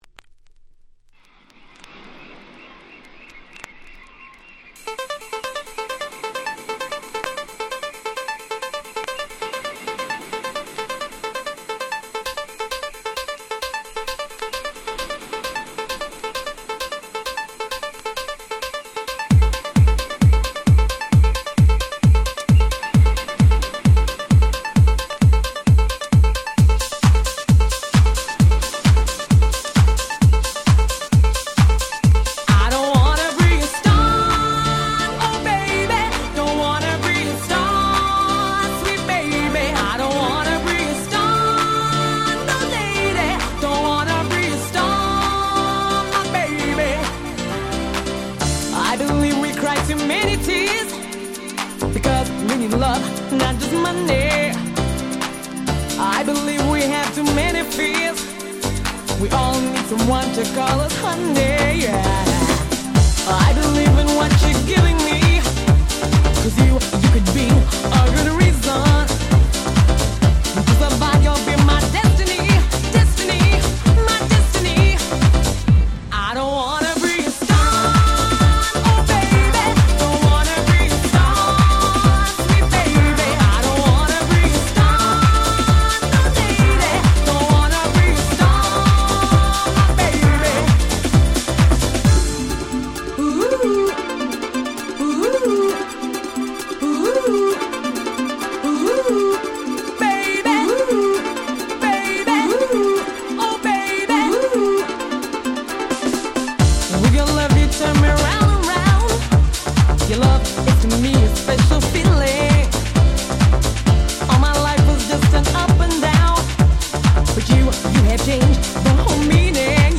95' Smash Hit Euro Dance / Dance Pop !!
90's ユーロダンス